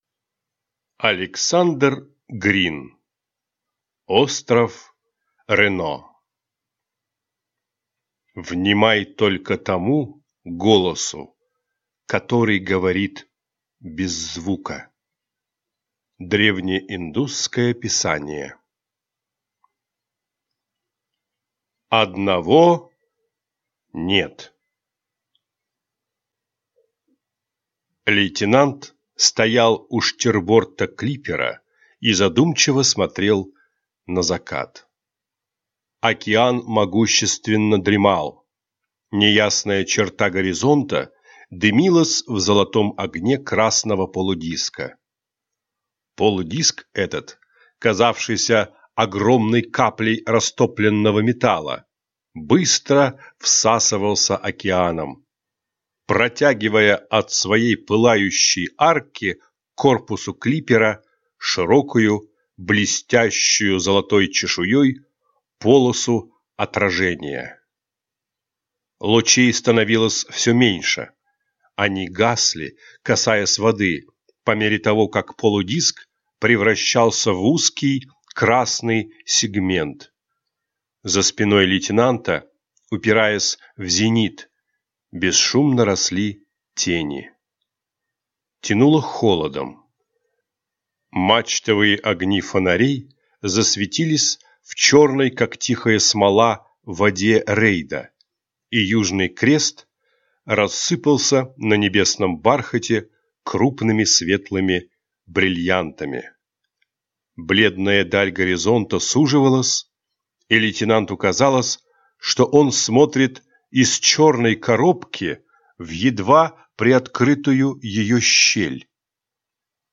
Аудиокнига Остров Рено | Библиотека аудиокниг